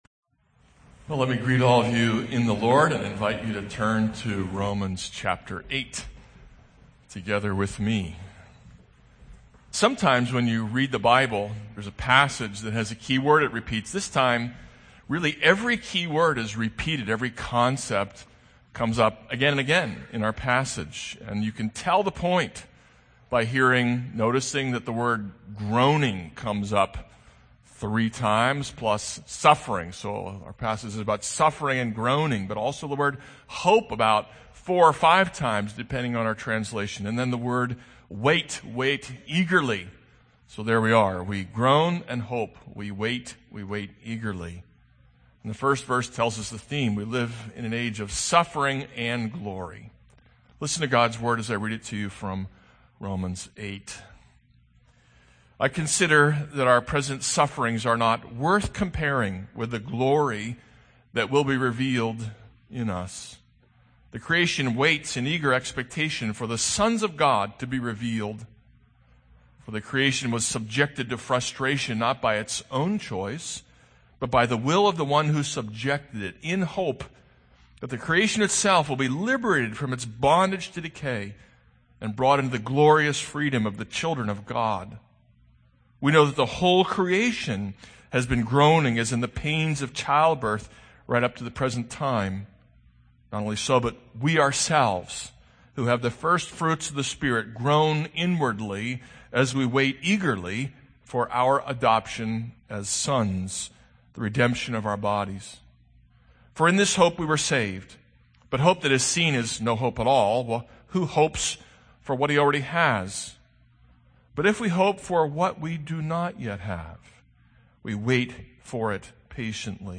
This is a sermon on Romans 8:18-27.